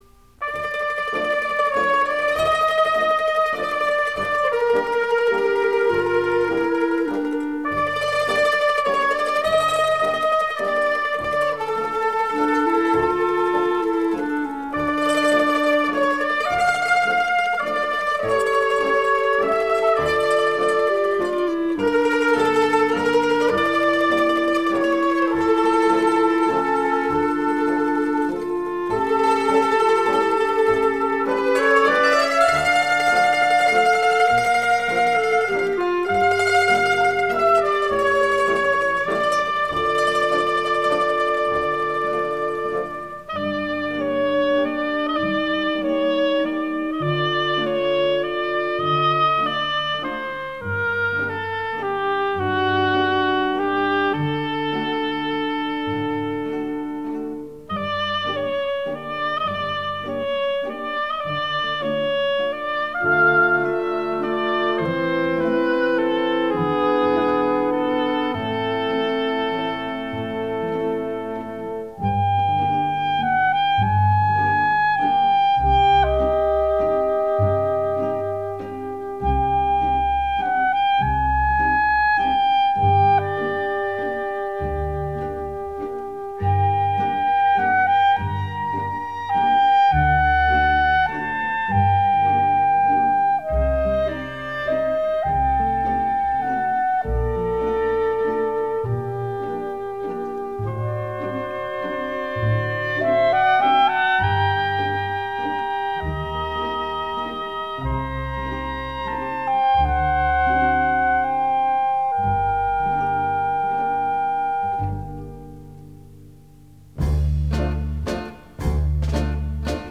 نسخه سنتوریش-_-